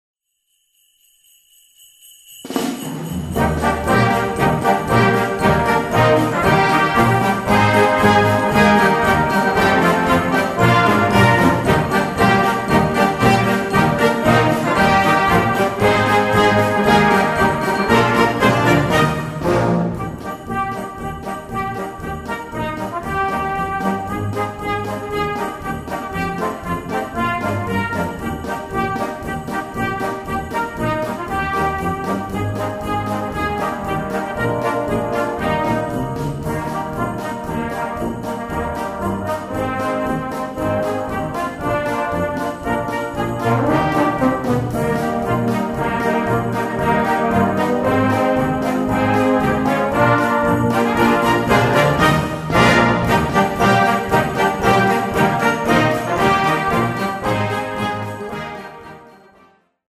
Gattung: Weihnachten
A4 Besetzung: Blasorchester Zu hören auf